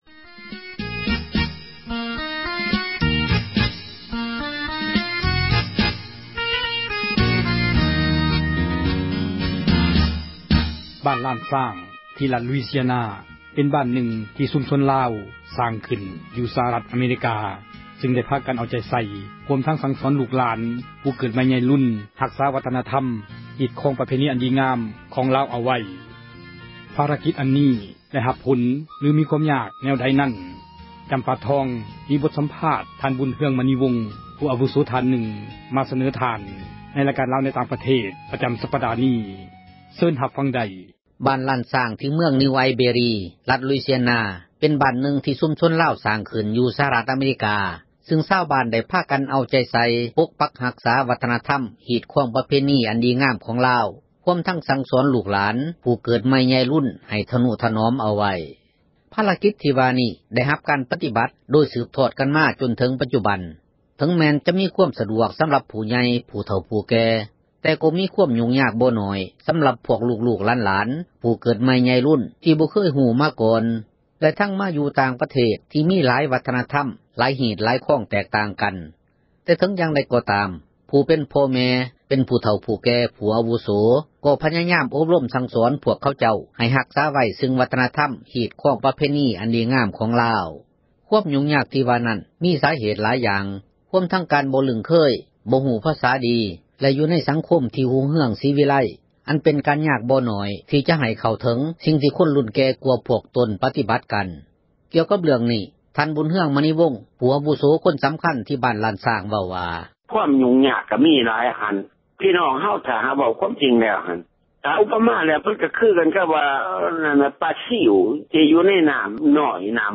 ບົດສໍາພາດ